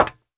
METAL 1C.WAV